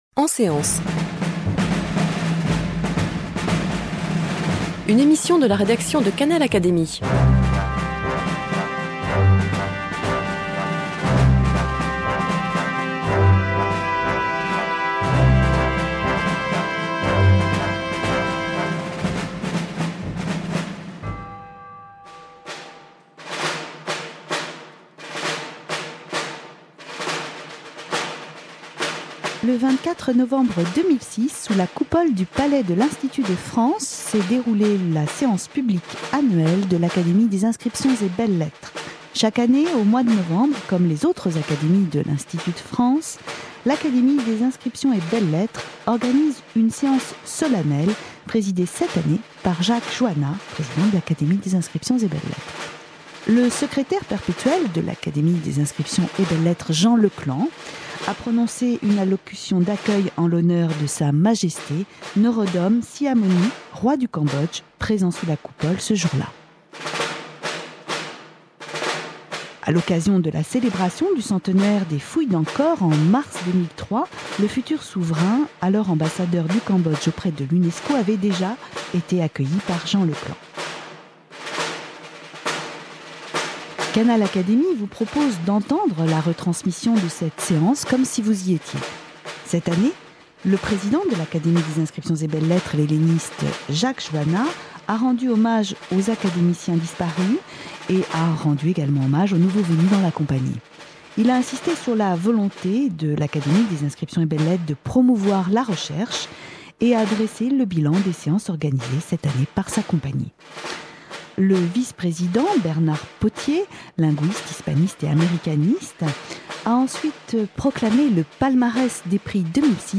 Sous la Coupole du palais de l’Institut de France, en présence de sa Majesté Norodom Sihamoni, Roi du Cambodge, l’Académie des inscriptions et belles-lettres, a proclamé le palmarès des Prix 2006 et dressé le bilan des actions menées au sein de l’Académie cette année.
Lors de la séance annuelle du 24 novembre 2006 qui se déroule selon l'usage, chaque année au mois de novembre, l'ancien Secrétaire perpétuel Jean Leclant a prononcé une allocution d'accueil en l'honneur du Roi du Cambodge, Norodom Sihamoni, récemment en visite d'Etat en France du 20 au 22 novembre 2006. Jean Leclant a évoqué la solidité des liens entre l'Ecole française d'Extrême-Orient et le Cambodge.